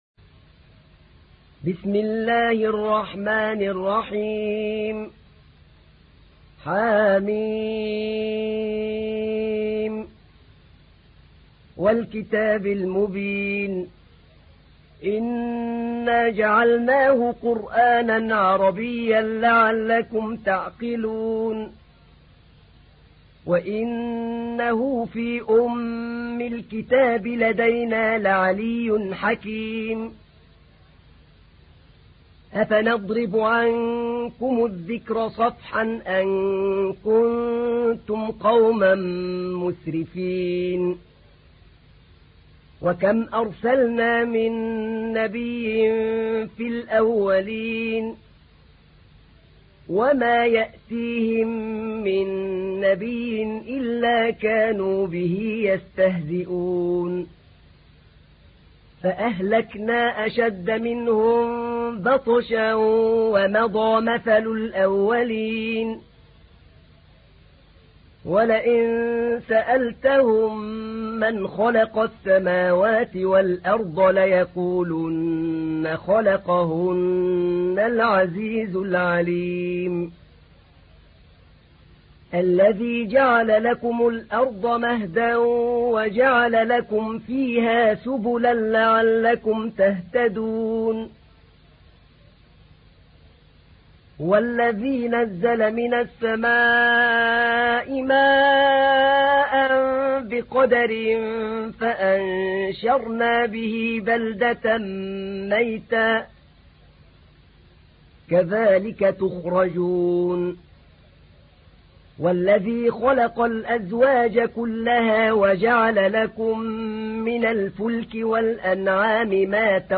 تحميل : 43. سورة الزخرف / القارئ أحمد نعينع / القرآن الكريم / موقع يا حسين